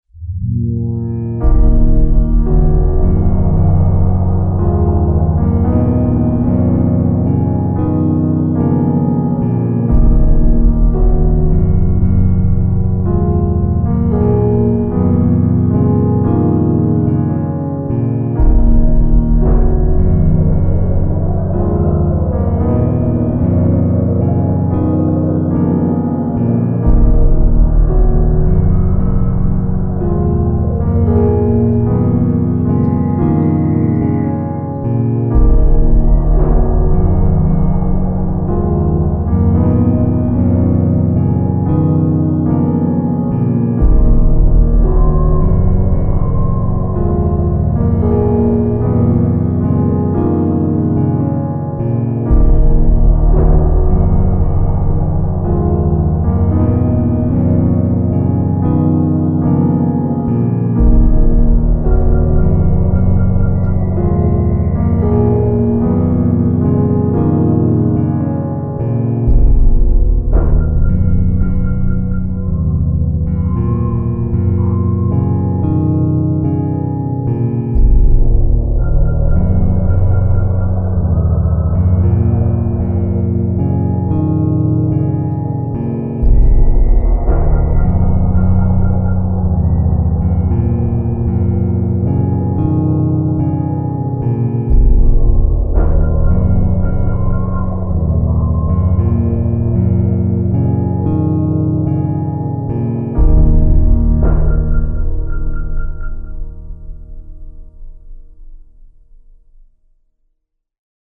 Ambient piano.